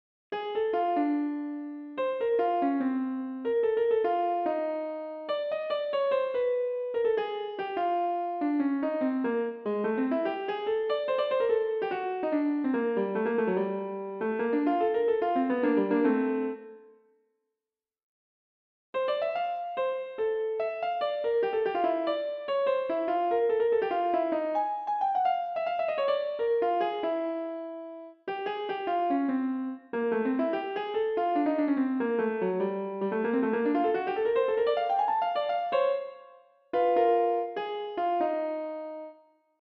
pianist
He had a lovely, laid back, bluesy style of playing.